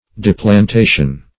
Search Result for " deplantation" : The Collaborative International Dictionary of English v.0.48: Deplantation \De`plan*ta"tion\, n. [Cf. F. d['e]plantation.]